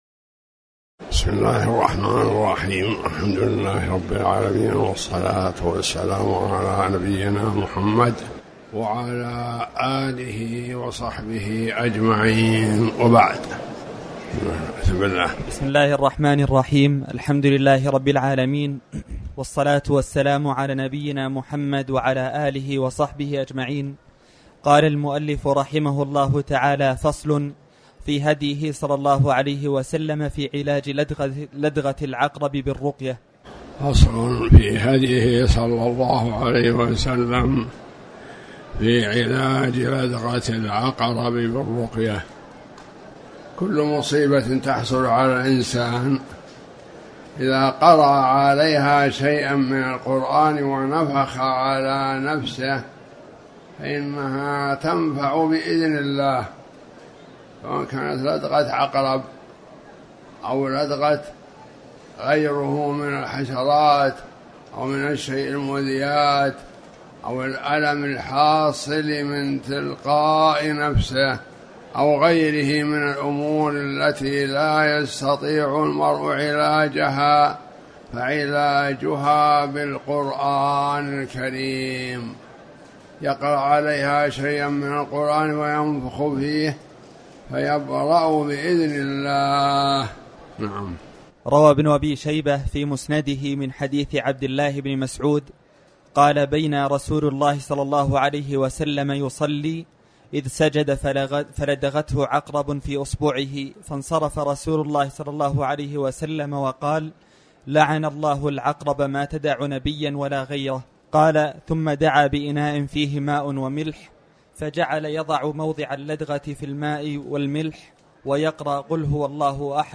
تاريخ النشر ٢٨ ذو القعدة ١٤٣٩ هـ المكان: المسجد الحرام الشيخ